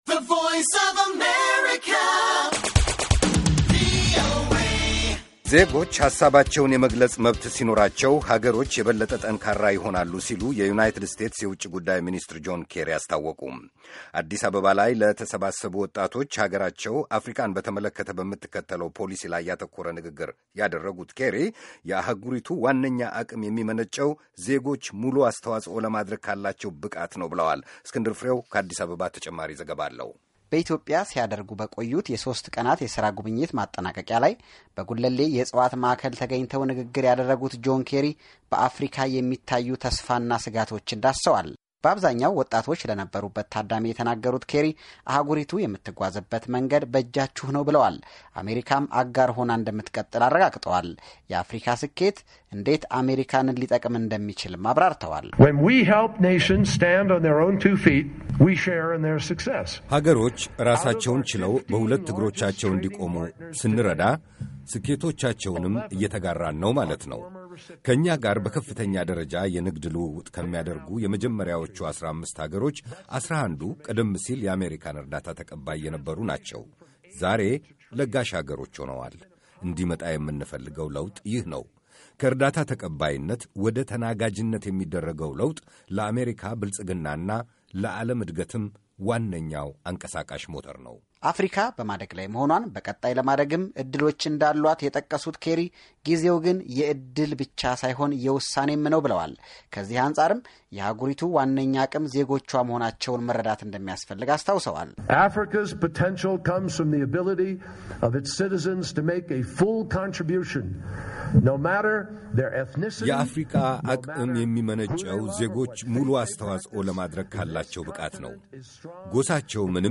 Jonh Kerry, Secretary of State, US. Africa Policy speech. Addis Ababa, Ethiopia, 05/03/14